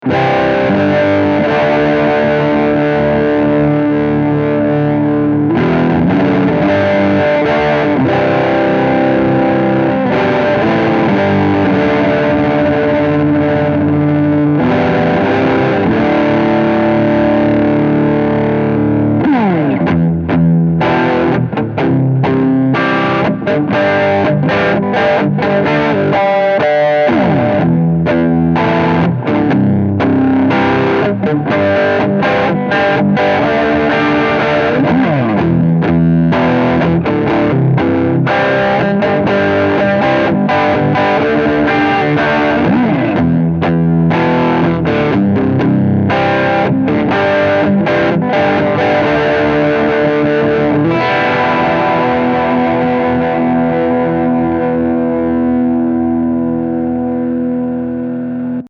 Here are 7 quick, 1-take MP3 sound files of myself playing this guitar, to give you an idea of what to expect. The guitar has great tone, sustain, and body, and also tonal variety, as the P90s add just the right amount of bite in the bridge position. The clean MP3 file is straight signal into the DI input on the Presonus ADL600 tube preamp, and the distorted files use a Doc Scary Overdrive unit, in to the ADL600, and Rosetta 200 convertersion, and MP3s were made in Logic using the stock "Warm Echoes" preset.